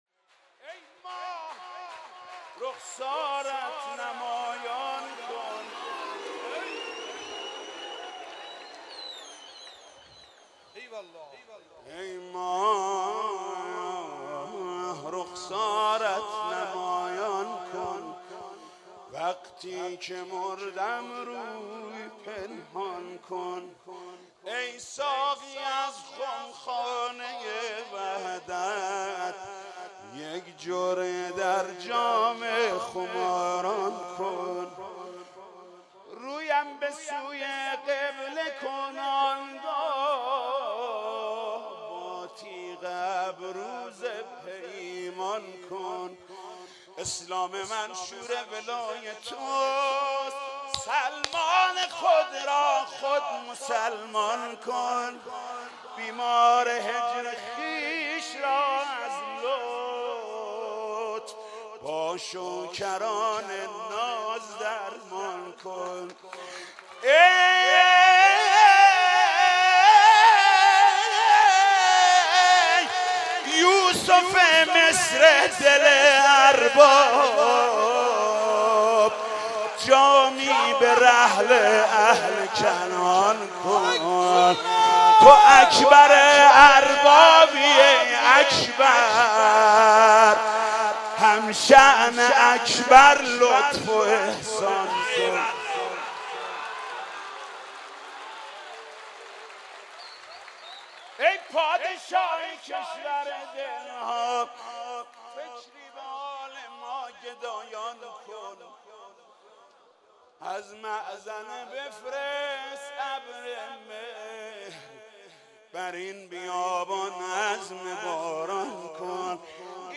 مدح: ای ماه رخسارت نمایان کن